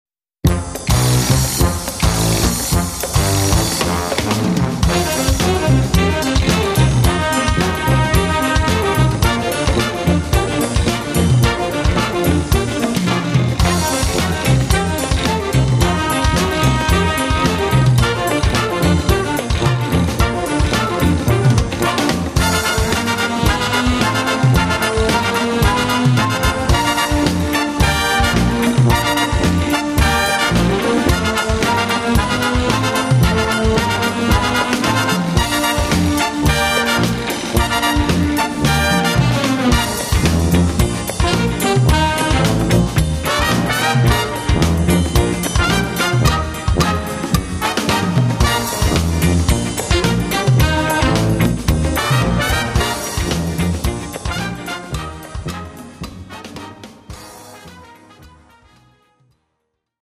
Gattung: Latino
Besetzung: Blasorchester